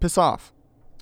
Update Voice Overs for Amplification & Normalisation
Voice Lines / Dismissive
piss off.wav